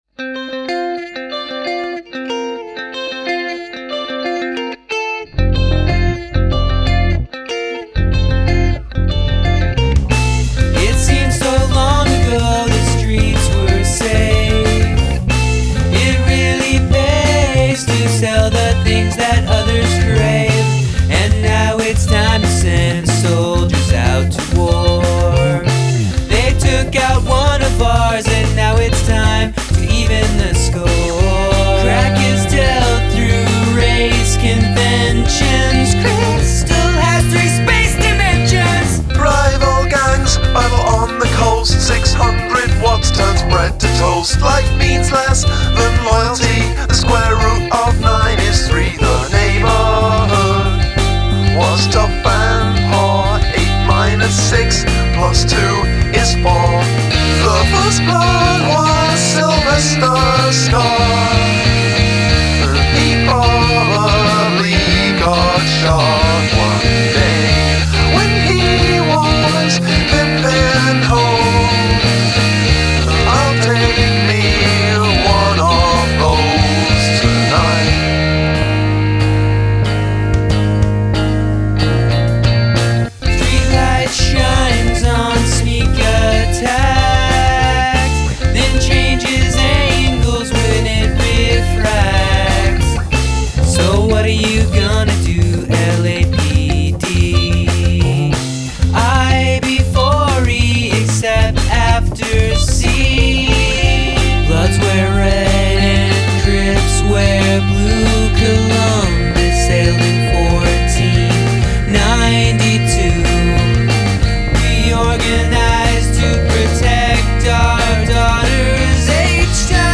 This is playful, loopy, and unpredictable.